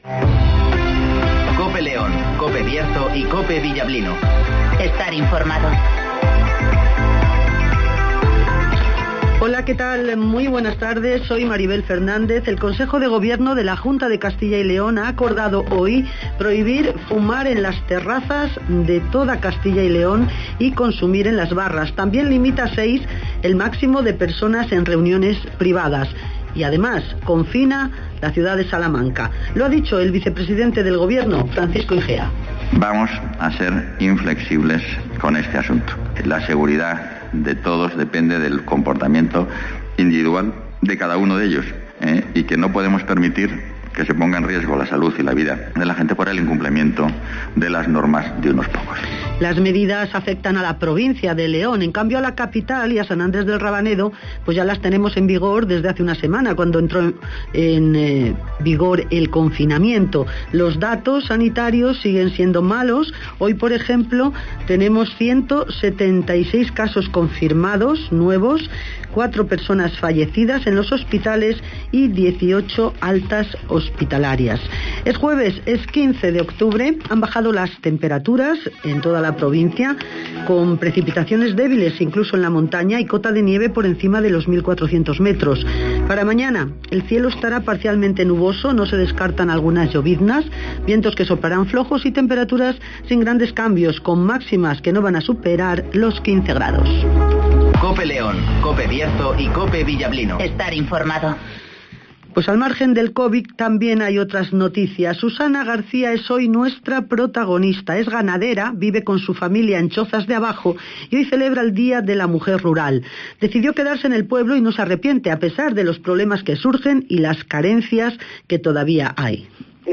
INFORMATIVOS
Conocemos las noticias de las últimas horas del Bierzo y León, con las voces de los protagonistas.